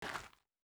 Shoe Step Gravel Medium C.wav